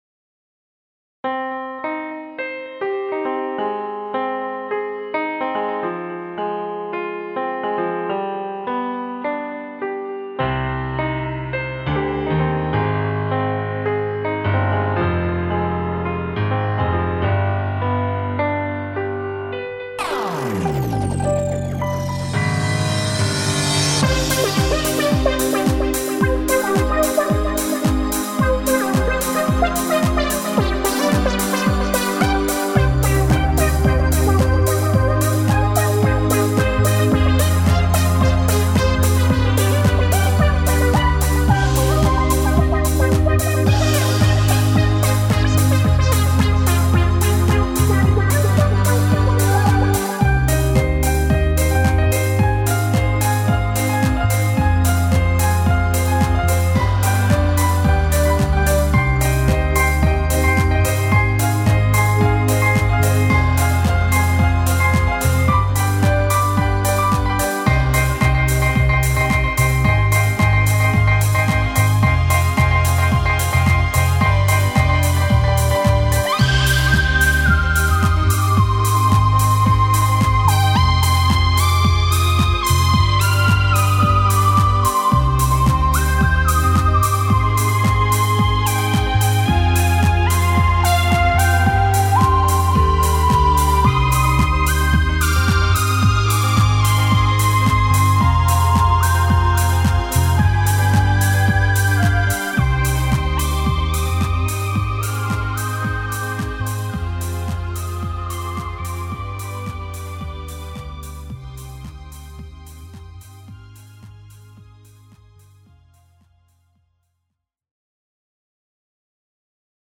..is it house, or what is it..?